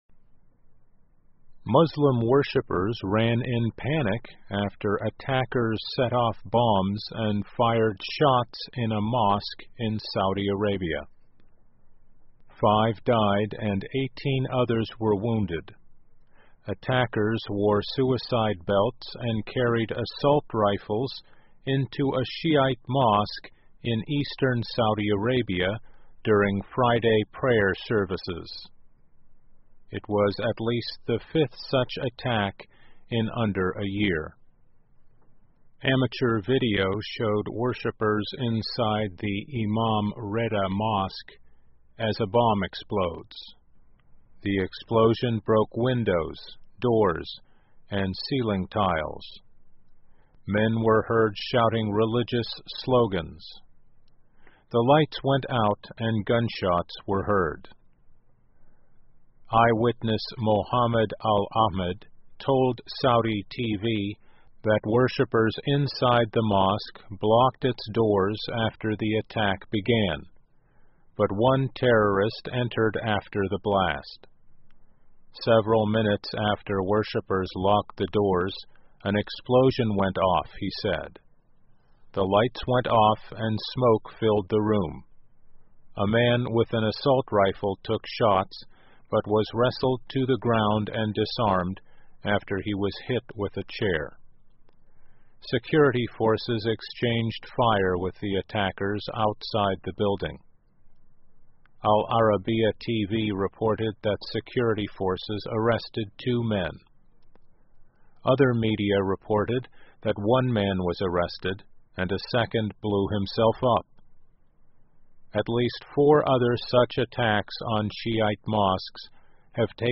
VOA慢速英语2016--沙特清真寺发生致命袭击案 听力文件下载—在线英语听力室